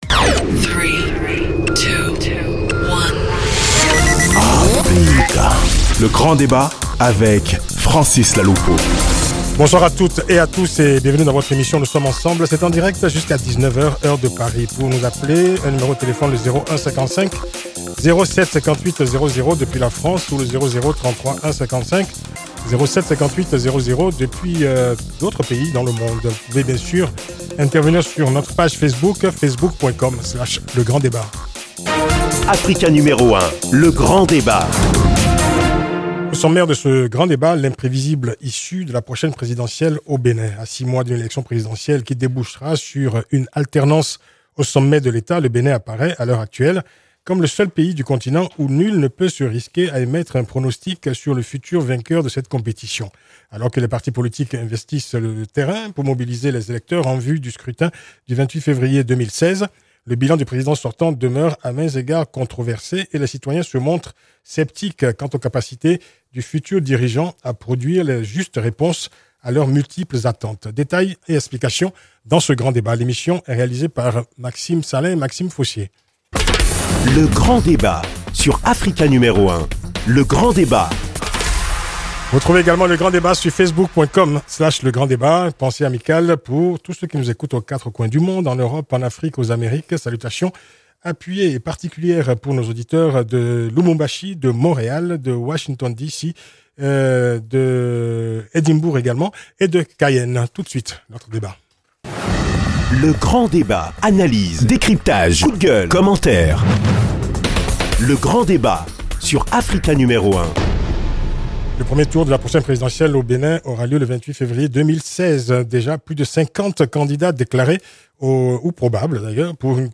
Il n’a pas fait de cadeau à Patrice Talon, hier jeudi 10 septembre 2015 sur la radio Africa N°1.
grand-debat-prc3a9sidence.mp3